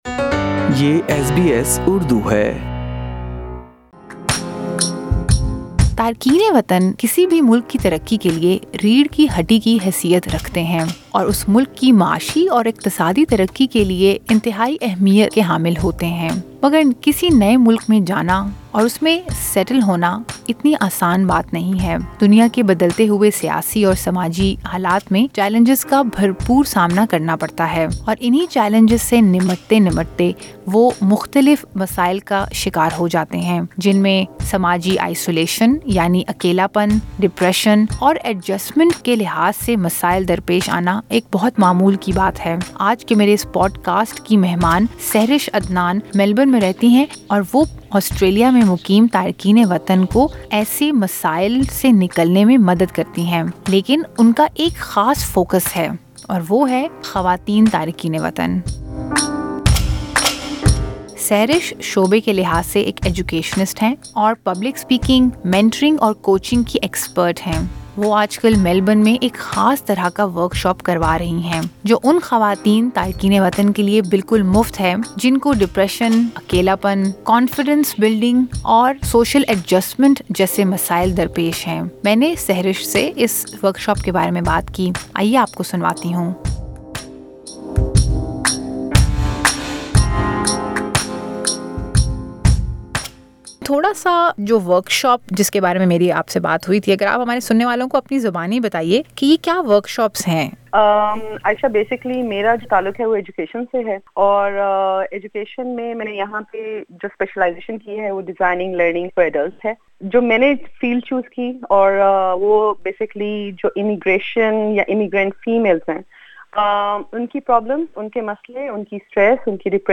While her audience is not restricted to a certain community, it primarily comprises Pakistani and Indian women. SBS Urdu talked to her about her innovative workshops that are a blend of discussions, learning and educational techniques, and confidence building exercises and activities.